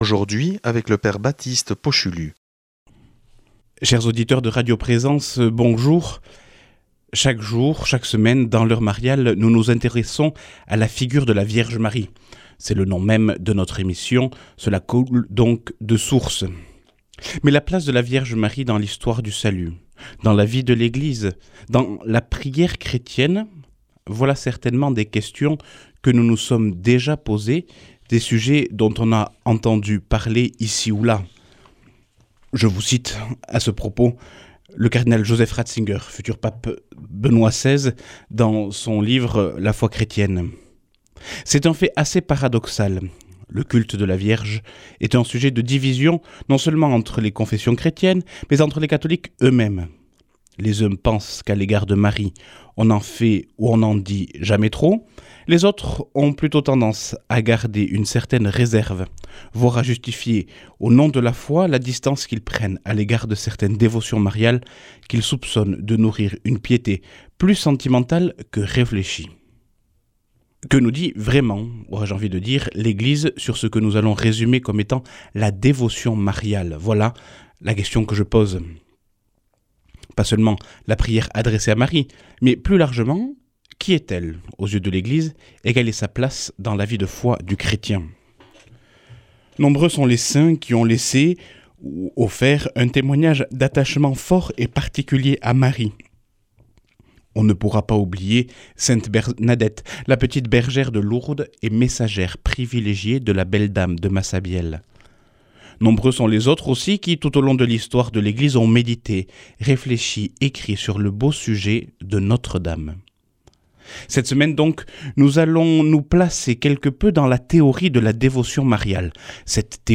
Enseignement Marial du 11 nov.